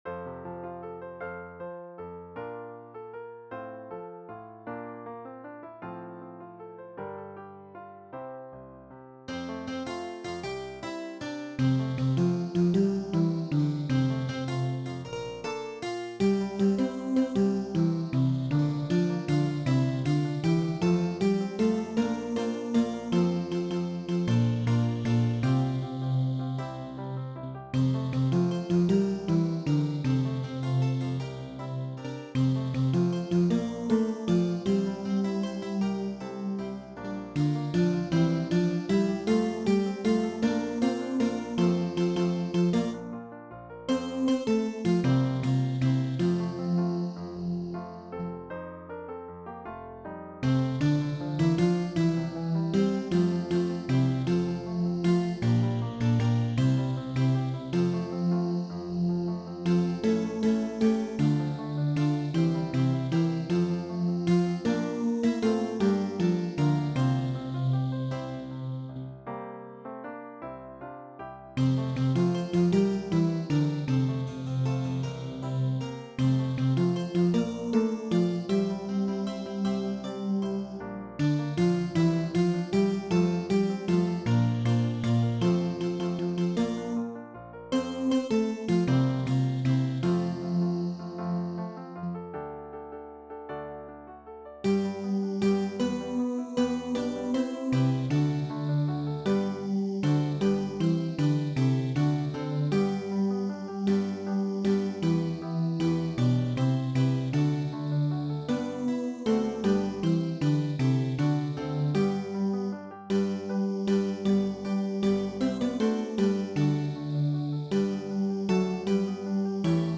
practice parts for Easter music
Your specific choir part is played on a sustaining flute sound. The other choir parts are played on a harpsichord and play the typical harpsichord clink but quickly fade away. The piano accompaniment is softer in the background.
Bass
MakeAJoyfulNoiseToTheLordBASS.WAV